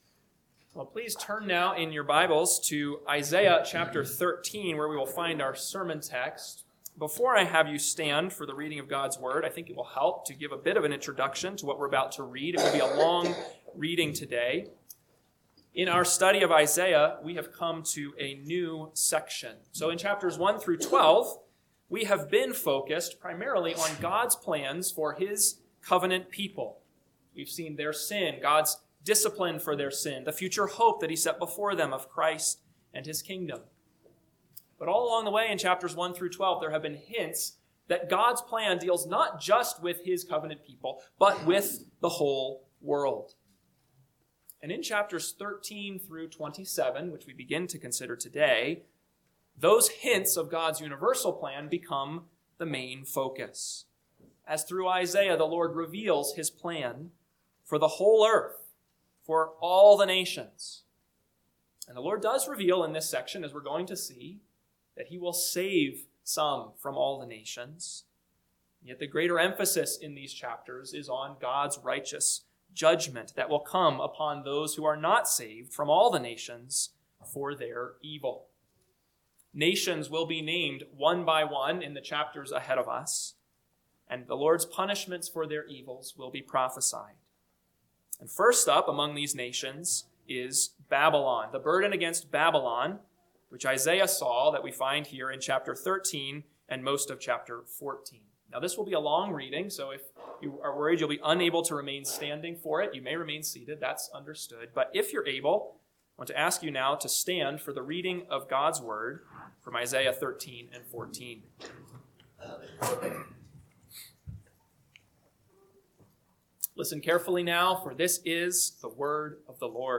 AM Sermon – 2/22/2026 – Isaiah 13:1-14:27 – Northwoods Sermons